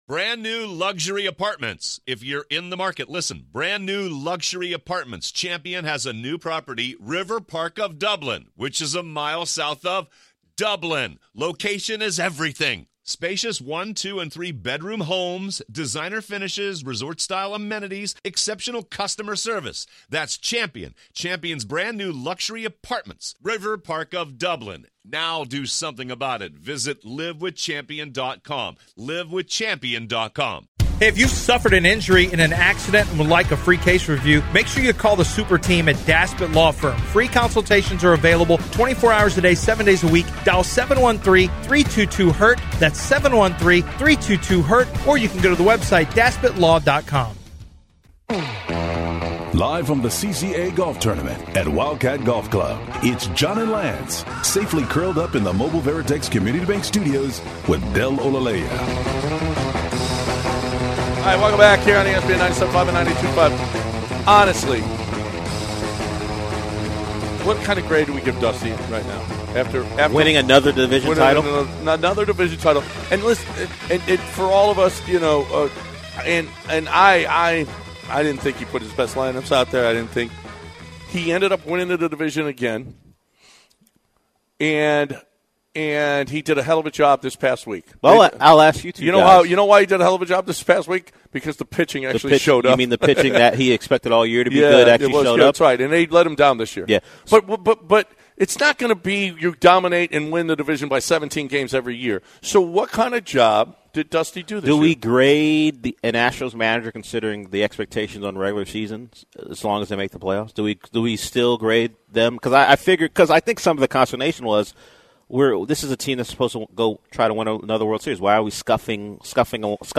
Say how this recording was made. LIVE from the Wildcat Golf Club!